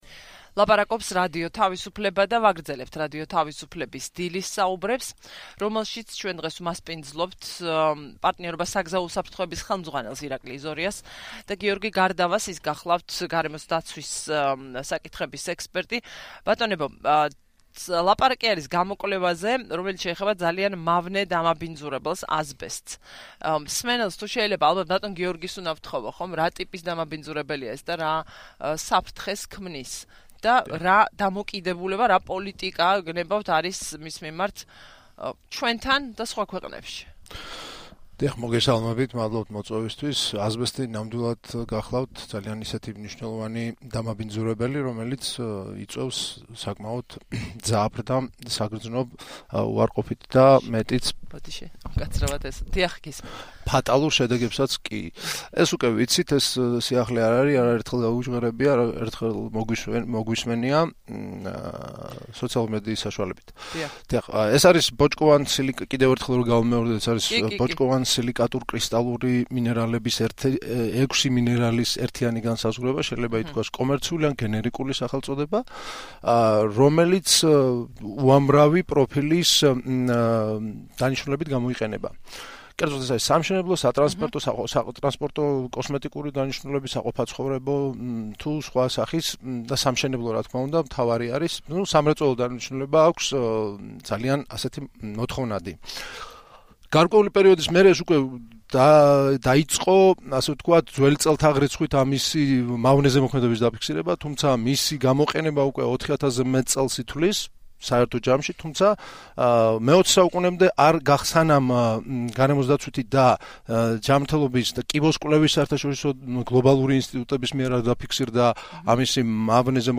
რადიო თავისუფლების ეთერში